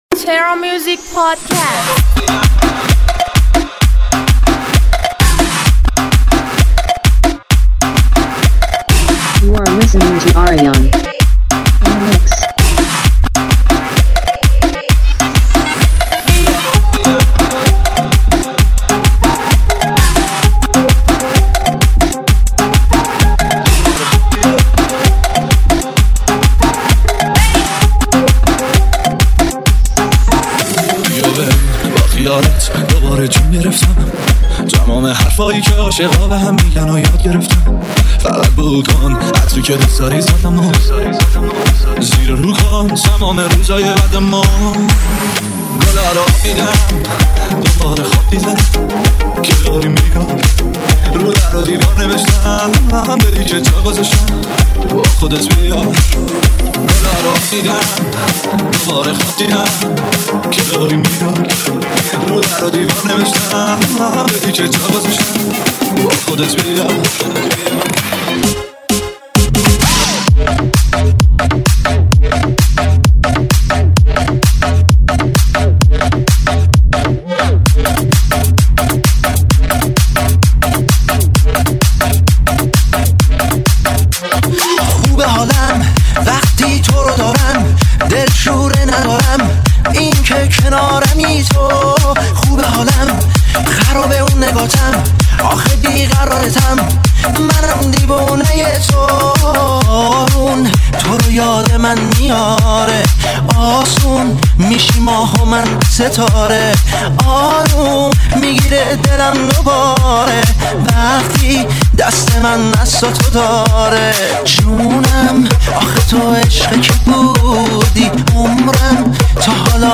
ریمیکس شاد تریبال مخصوص رقص
ریمیکس شاد رقص و دنس